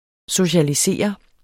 Udtale [ soɕaliˈseˀʌ ]